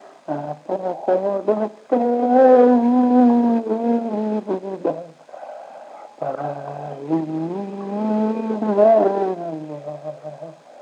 Мягкое цоканье (совпадение литературных аффрикат /ц/ и /ч’/ в мягком /ц’/)
/о”-ц’и йа”-сы-ны-т’и уу-йе”й да-соо-ко-л’и”-ныы-йа
А-бро”-в’и ц’е”-рны-т’ии уу-йее”й даа-со-бо-л’иии”-ныыы-йа/